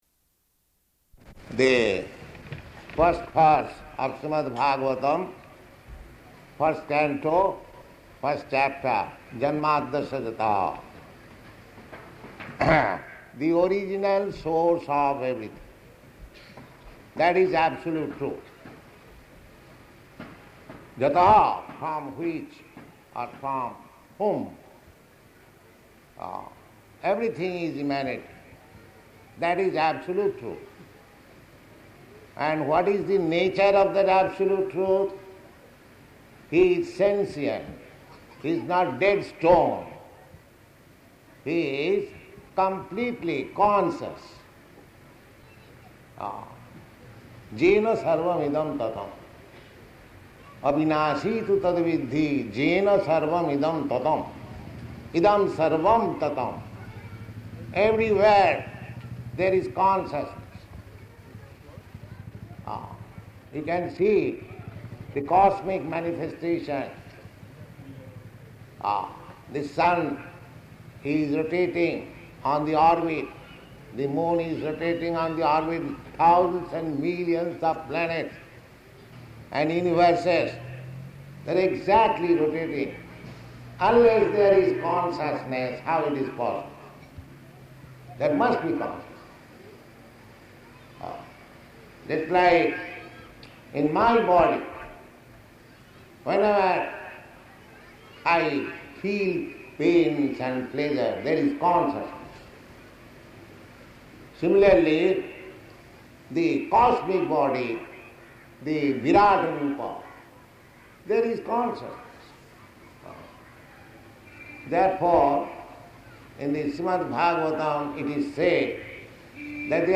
Location: Bombay